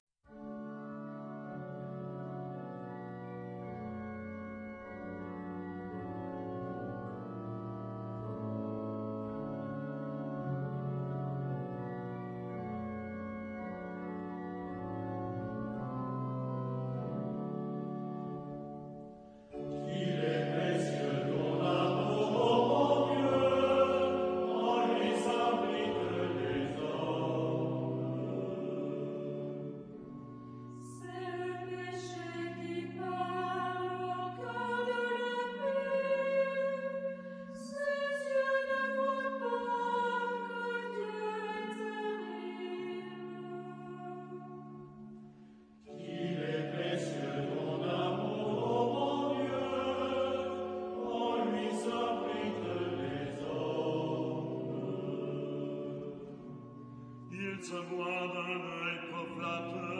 Genre-Style-Forme : Sacré ; Psaume
Type de choeur : SATB  (4 voix mixtes OU unisson )
Instrumentation : Orgue  (1 partie(s) instrumentale(s))
Instruments : Orgue (1)
Tonalité : ré majeur